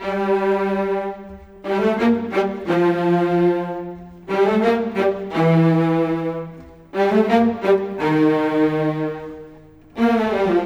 Rock-Pop 07 Cello _ Viola 01.wav